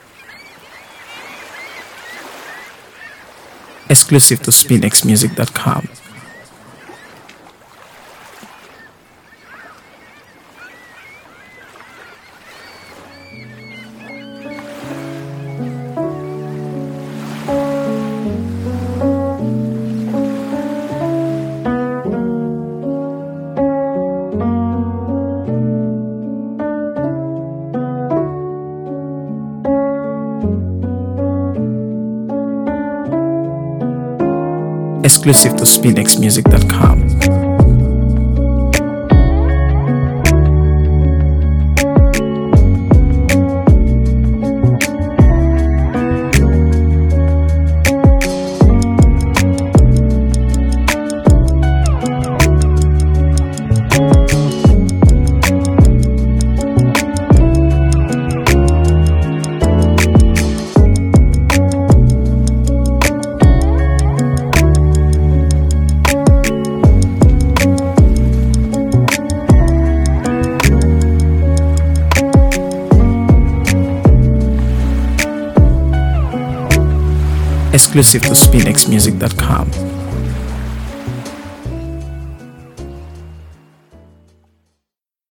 AfroBeats | AfroBeats songs
Nigerian singer and songwriter